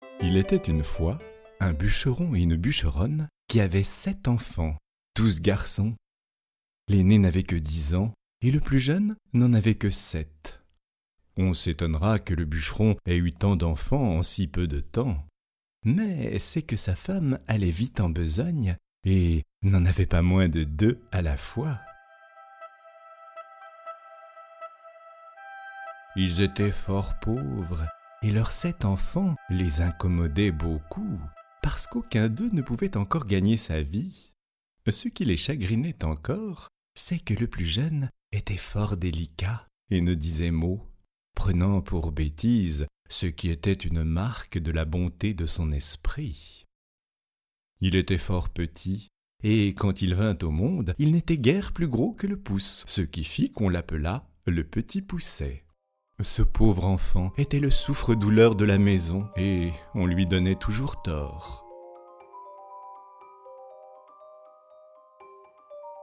Une illustration sonore accompagne la lecture audio.